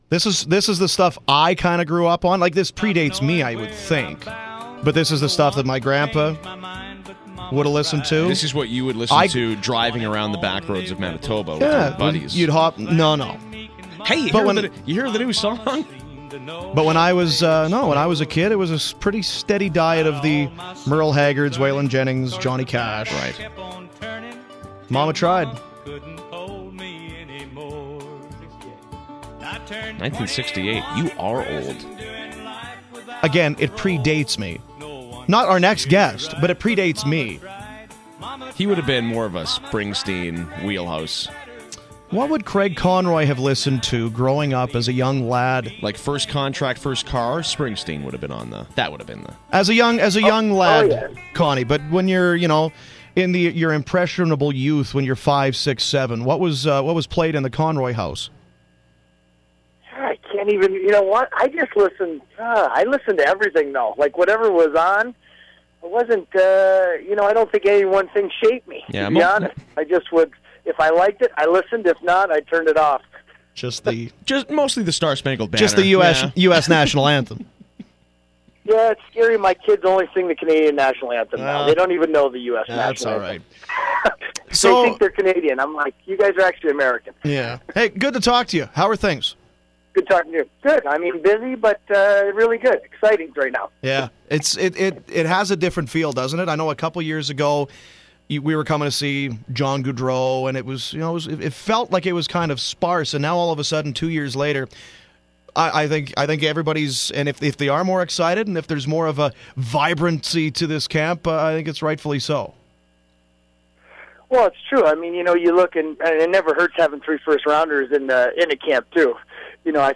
Craig Conroy at development camp